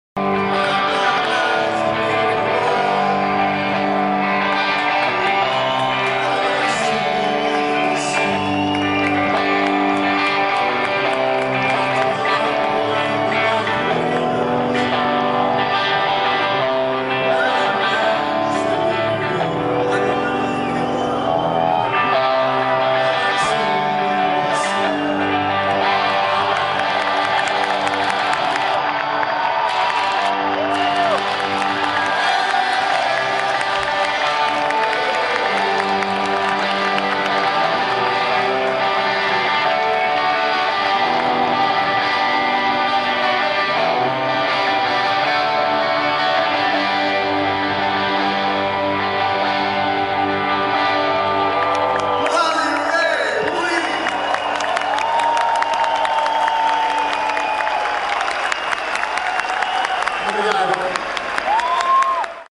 E aí, quem curtiu o dueto?